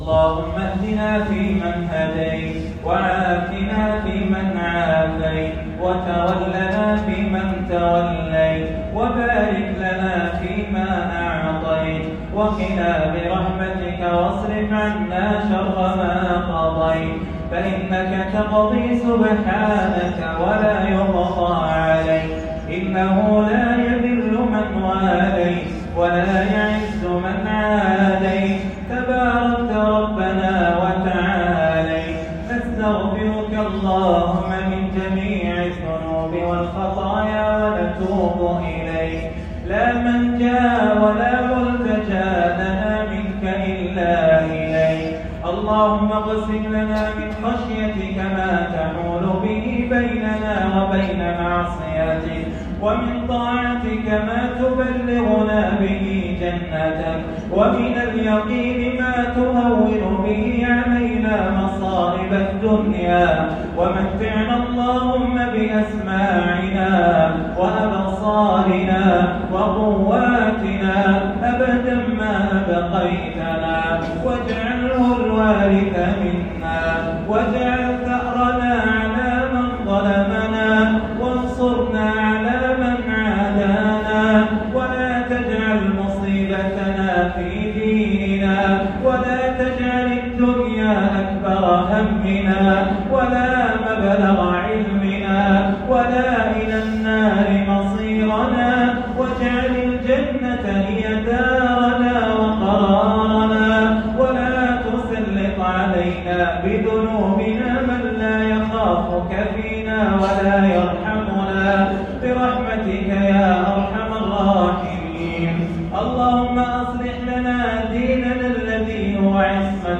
جديد - قنوت ليلة ١٦ رمضان ١٤٤٤هـ.